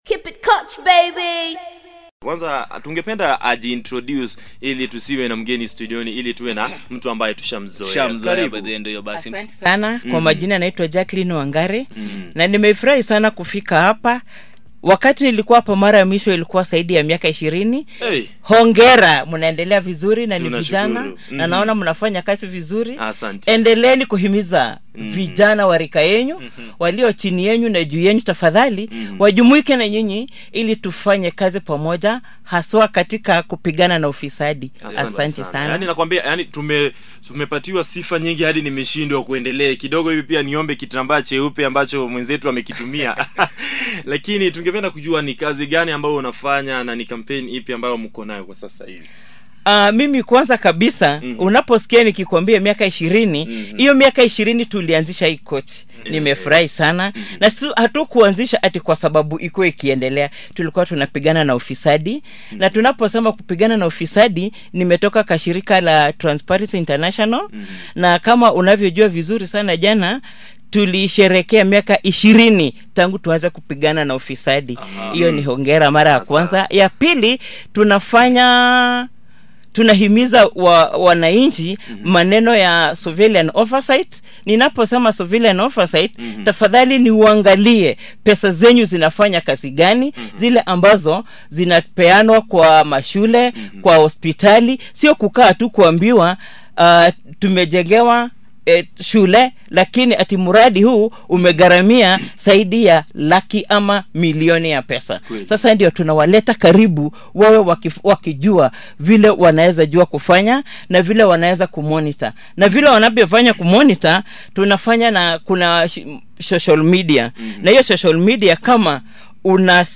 Talk show on how to address transparency and accountability issues using social media